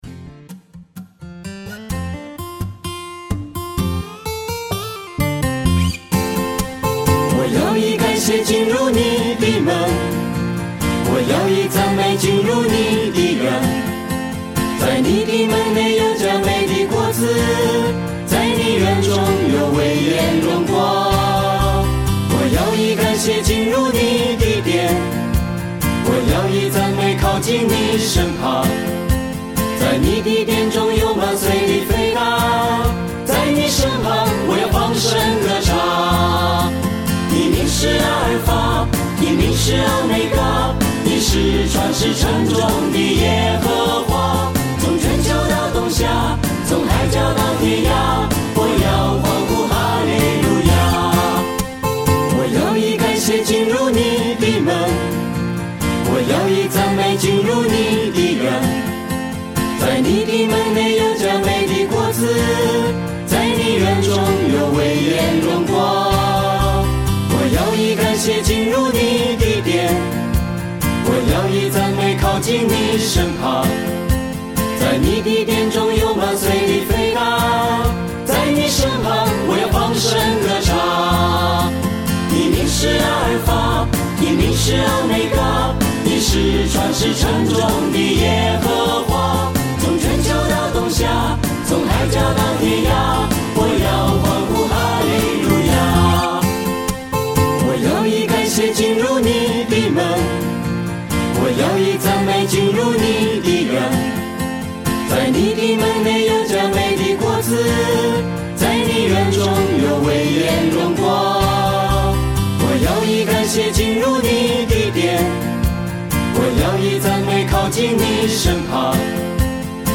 敬拜 080824